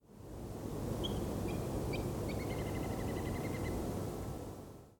Little Grebe (Tachybaptus ruficollis)
1 – trill or song
A fast trill, usually preceded by 3 or so higher pitched “pips”.
Little Grebe trills are often slightly slower, longer and sometimes less regularly spaced than Whimbrel calls.
Little Grebe flight trill
clip_lg_song.mp3